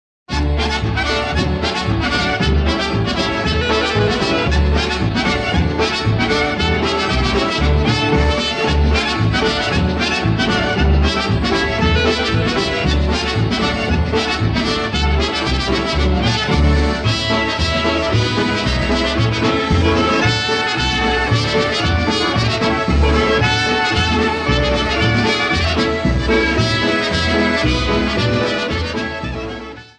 Media > Music > Polka, General